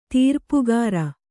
♪ tīrpugāra